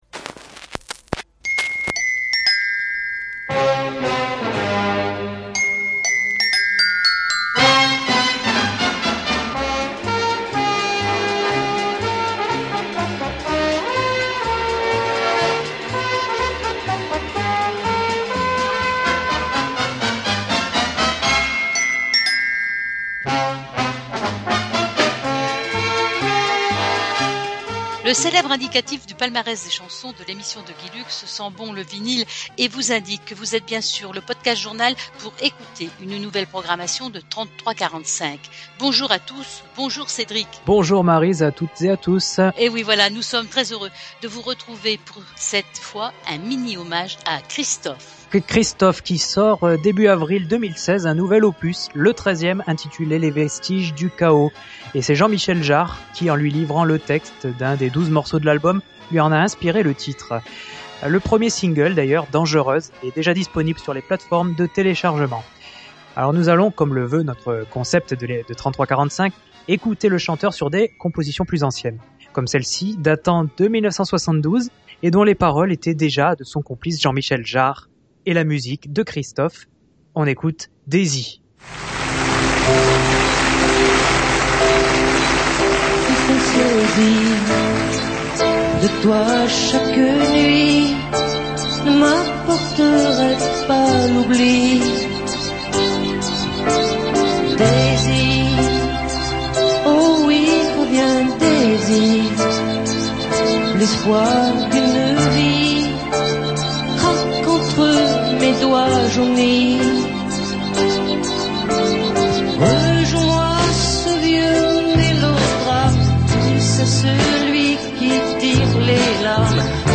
Le Podcast Journal vous propose cette émission musicale dédiée aux années vinyles
Cette émission "33-45" aura pour invité d'honneur l'auteur-compositeur interprète Christophe.